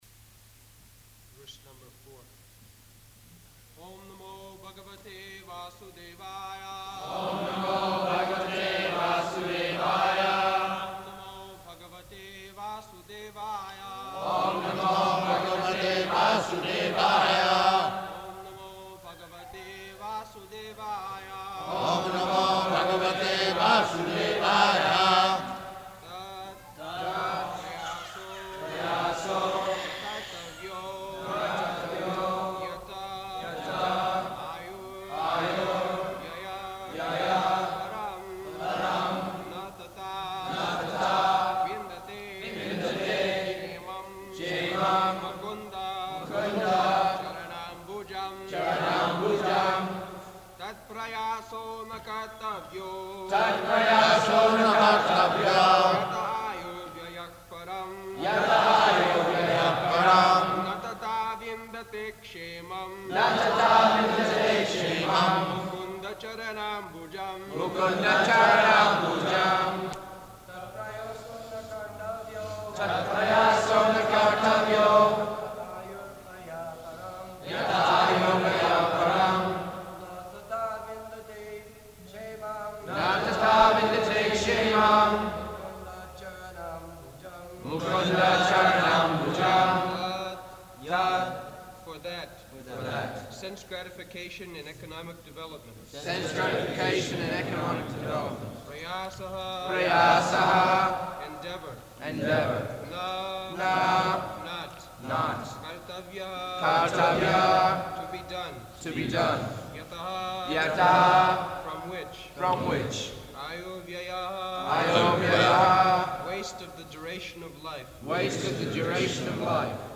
June 20th 1976 Location: Toronto Audio file
[Devotees repeat] [leads chanting, etc.]